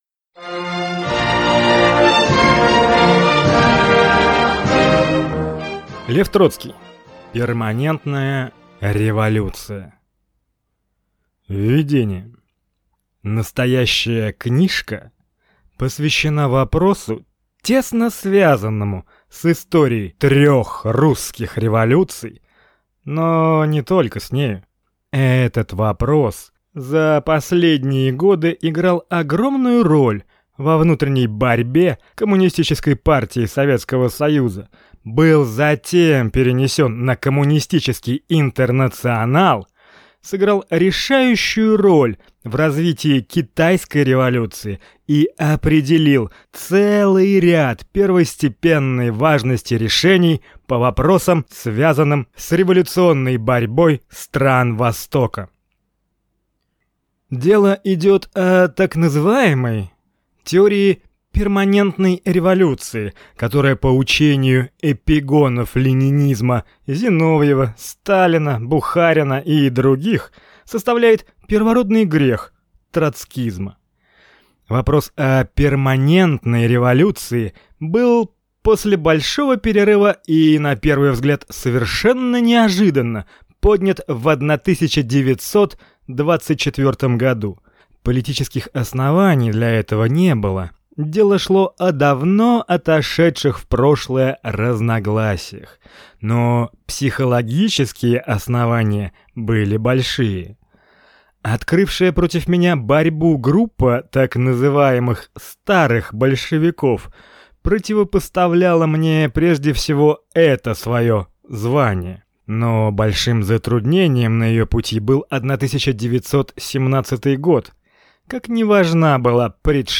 Аудиокнига Перманентная революция | Библиотека аудиокниг